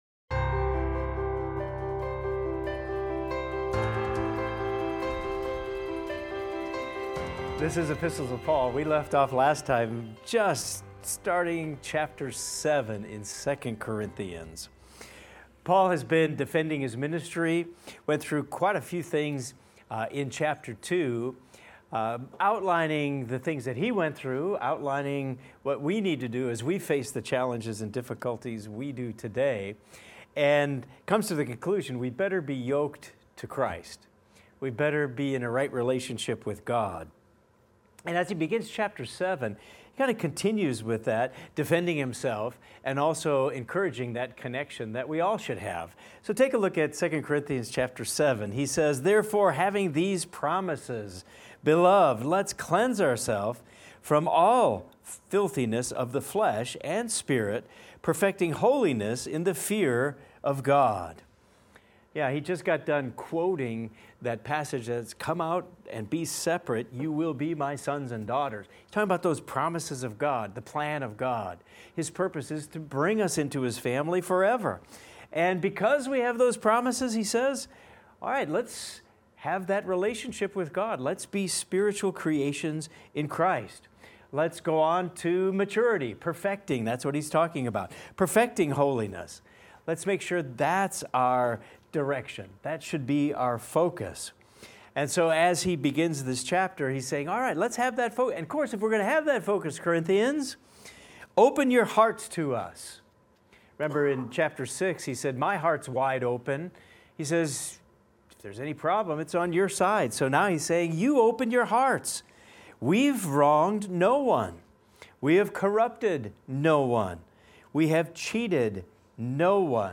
In this class we will discuss 2 Corinthians 7:1 thru 2 Corinthians 8:24 and examine the following: Paul urges believers to pursue holiness out of reverence for God.